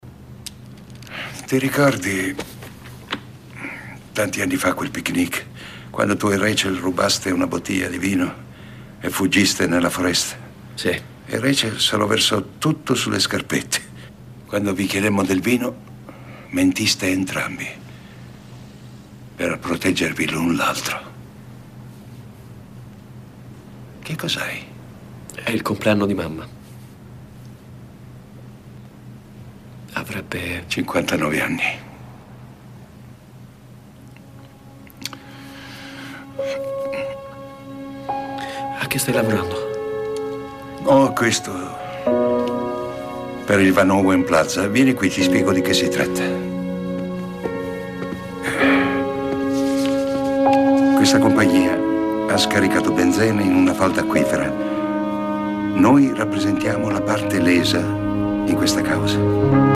voce di Vittorio Di Prima nel telefilm "The Guardian", in cui doppia Dabney Coleman.